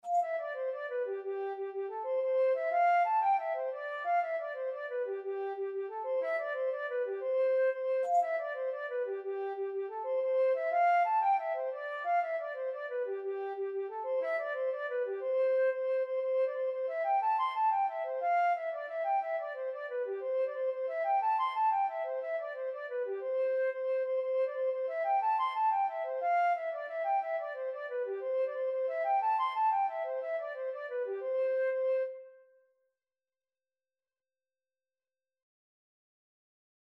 Free Sheet music for Flute
6/8 (View more 6/8 Music)
C major (Sounding Pitch) (View more C major Music for Flute )
Flute  (View more Easy Flute Music)
Traditional (View more Traditional Flute Music)